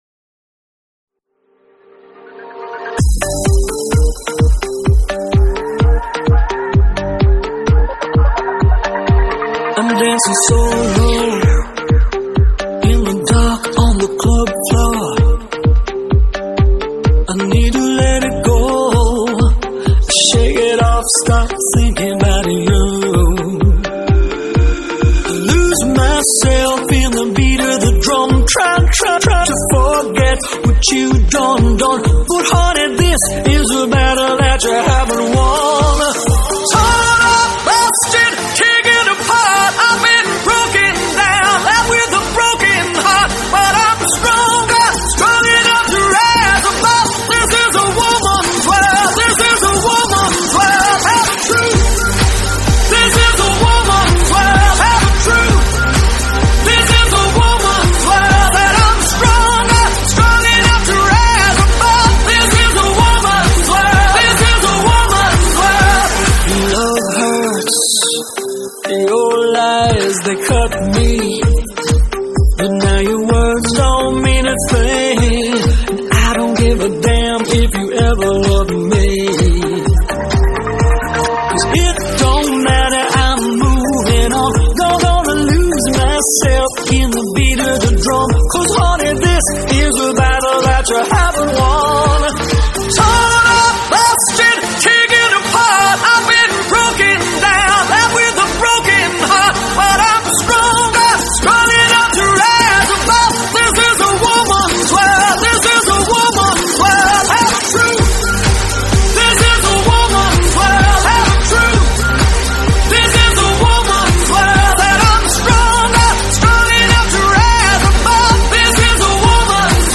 Pop, Dance-Pop